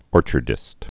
(ôrchər-dĭst)